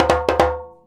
100DJEMB03.wav